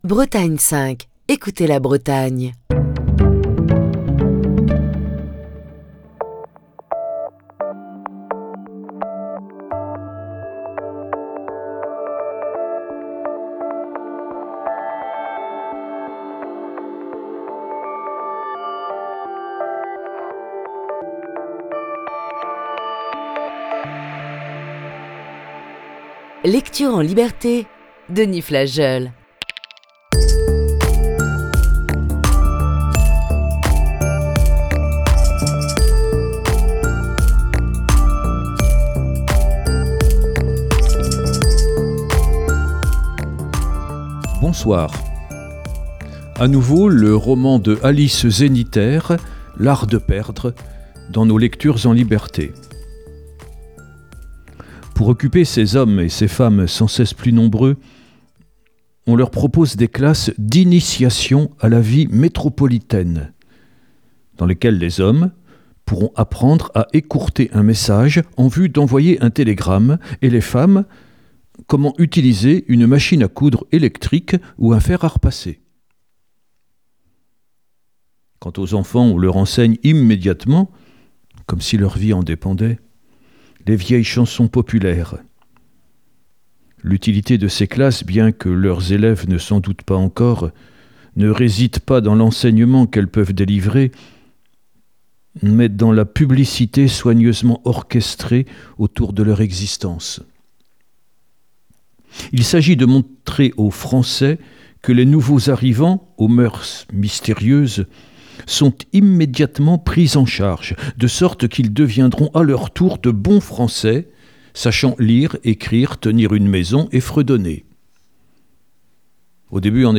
Émission du 16 février 2022.